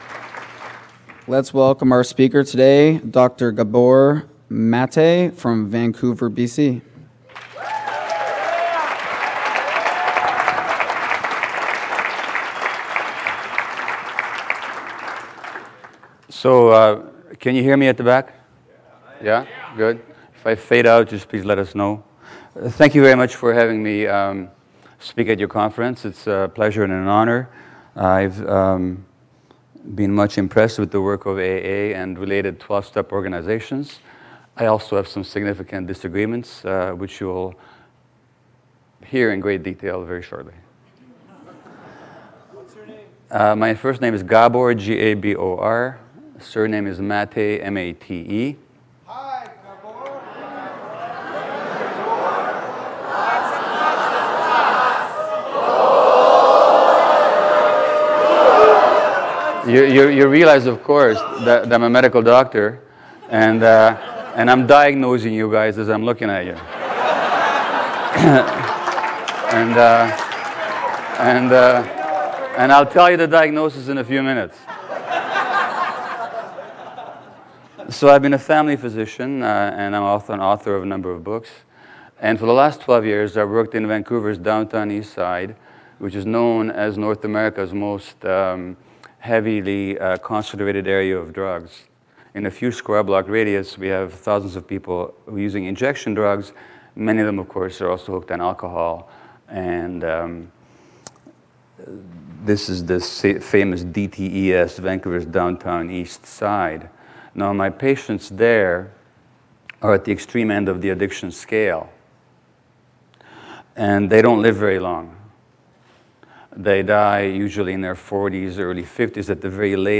ICYPAA 53 – San Francisco, CA 2011 – The New Dr.’s Opinion Panel